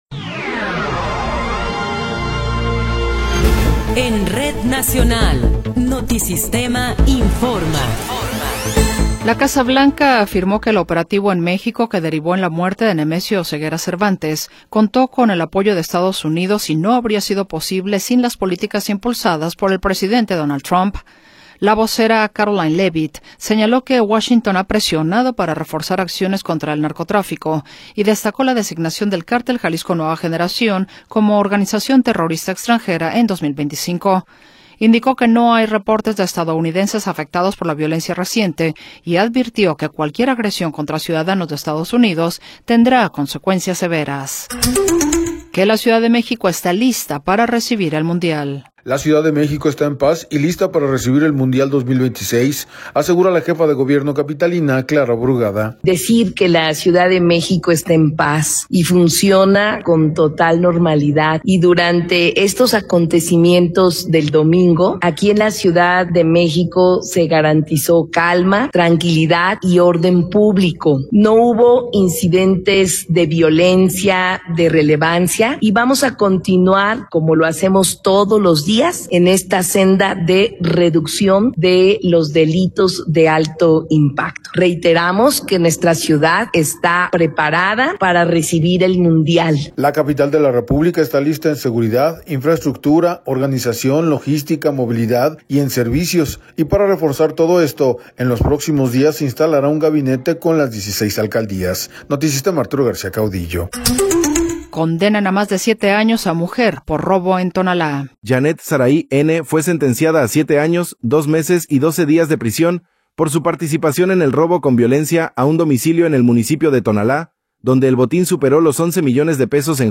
Resumen informativo Notisistema, la mejor y más completa información cada hora en la hora.